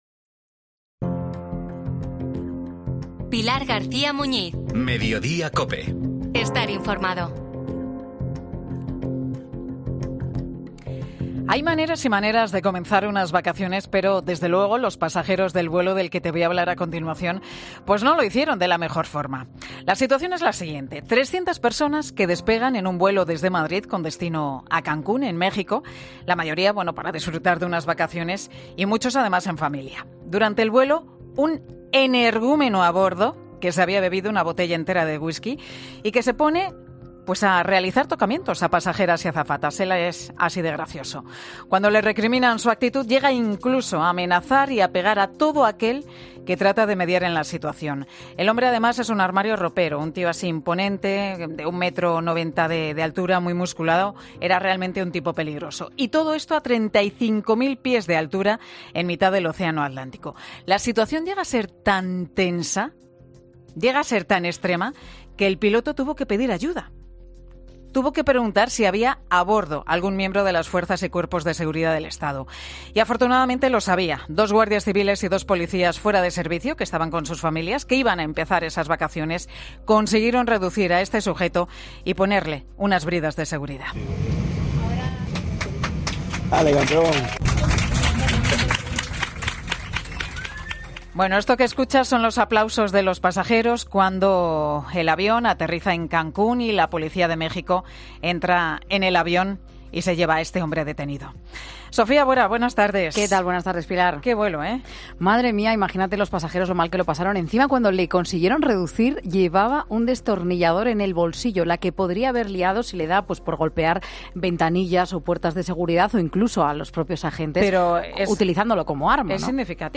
Pilar García Muñiz explica los protocolos que deben cumplir las compañías aéreas cuando se encuentran con un pasajero que infringe las normas o viaja ebrio